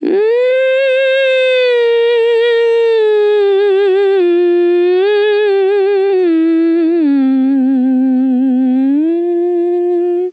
TEN VOCAL FILL 20 Sample
Categories: Vocals Tags: dry, english, female, fill, sample, TEN VOCAL FILL, Tension